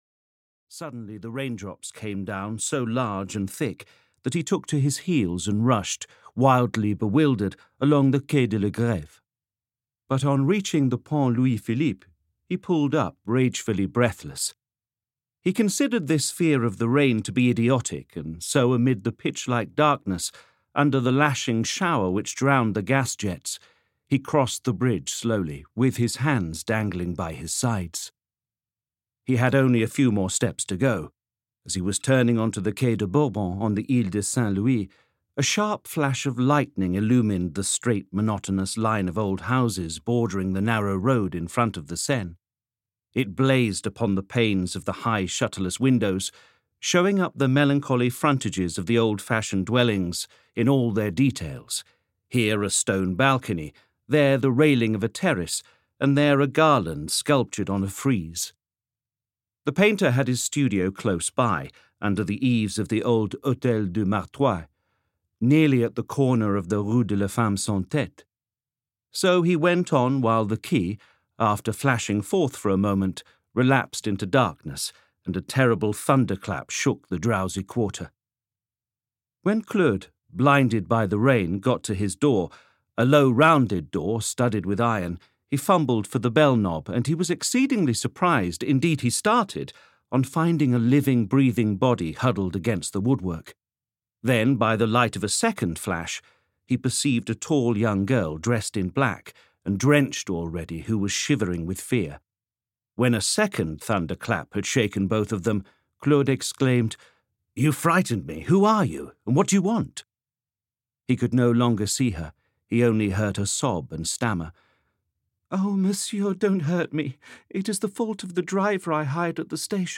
The Masterpiece (EN) audiokniha
Ukázka z knihy